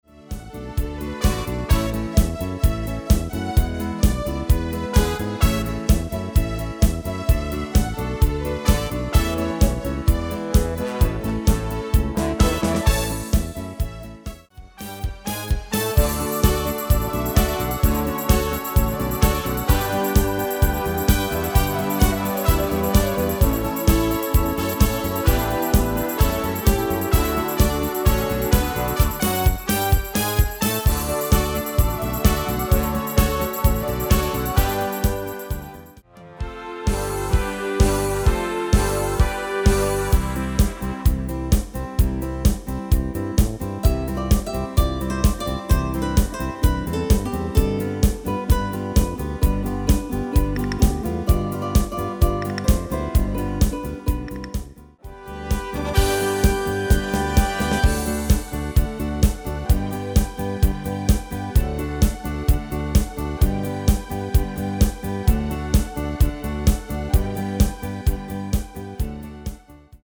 Demo/Koop midifile
Genre: Evergreens & oldies
Toonsoort: A/D/C/G/E/A
- Vocal harmony tracks
Demo's zijn eigen opnames van onze digitale arrangementen.